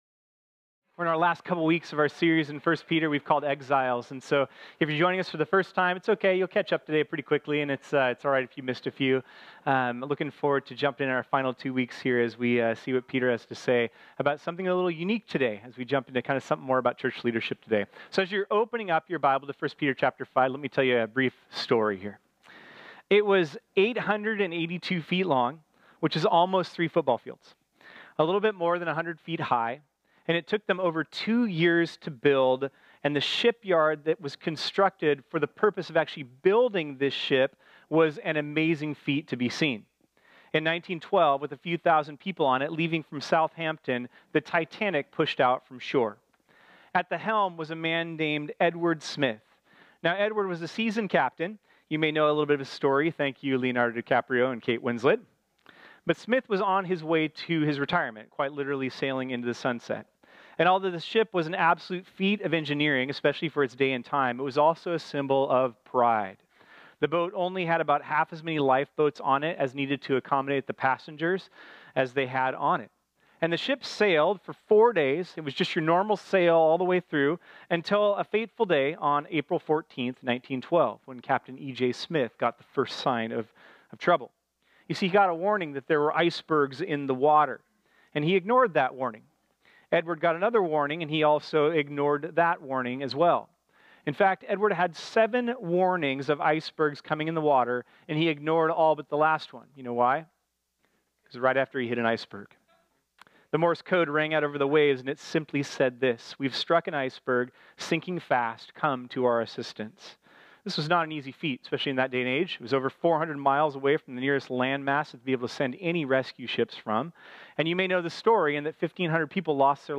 This sermon was originally preached on Sunday, April 22, 2018.